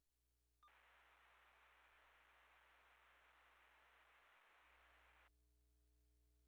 P25 Wide Pulse raw encrypted audio.
P25-widepulse-enc.wav